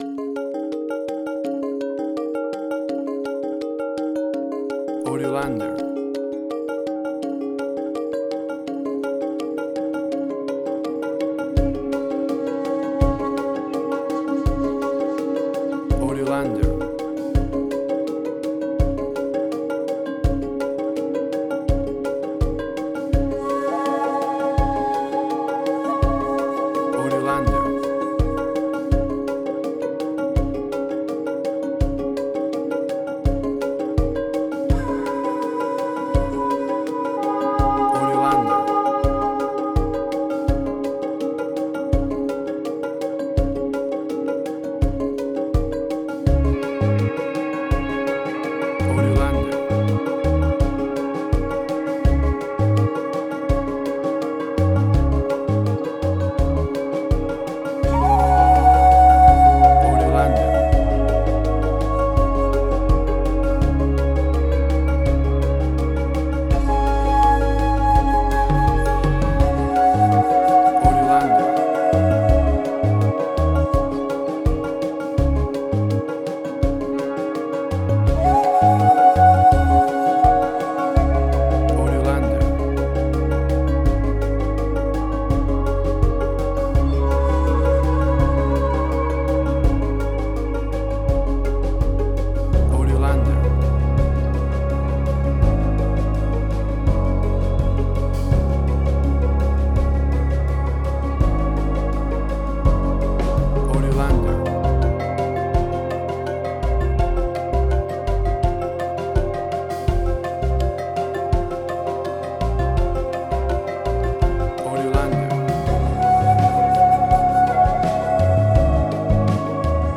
World Ambient Similar City of Ghosts Supernatural.
Tempo (BPM): 83